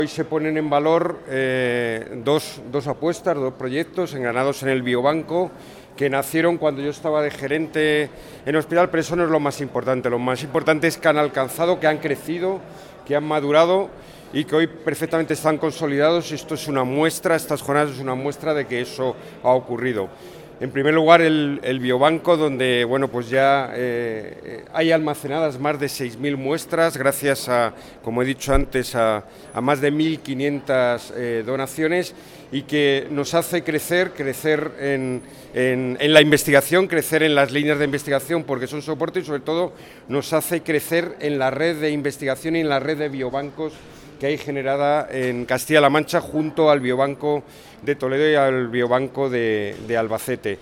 Consejería de Sanidad Jueves, 3 Abril 2025 - 10:30am El director gerente del SESCAM, Alberto Jara, ha destacado en la inauguración de las jornadas del Biobanco en Ciudad Real que este servicio del HGUCR está ya plenamente consolidado. En la actualidad almacena más de 6.000 muestras gracias a 1.500 donaciones que están permitiendo desarrollar la investigación en nuestra región. jara_biobanco_cr.mp3 Descargar: Descargar